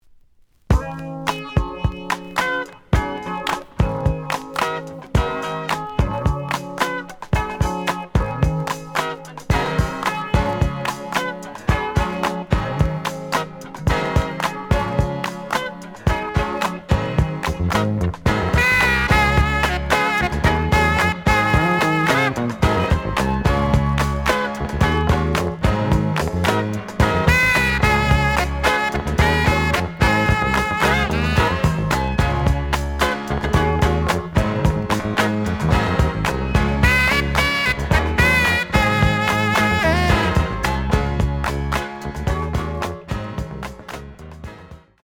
試聴は実際のレコードから録音しています。
The audio sample is recorded from the actual item.
●Genre: Jazz Funk / Soul Jazz